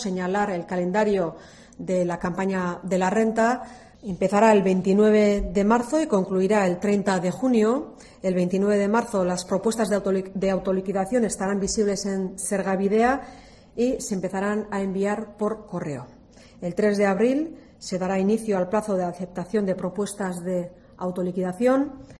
Eider Mendoza, portavoz de la Diputación de Gipuzkoa